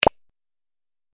دانلود آهنگ آب 59 از افکت صوتی طبیعت و محیط
دانلود صدای آب 59 از ساعد نیوز با لینک مستقیم و کیفیت بالا
جلوه های صوتی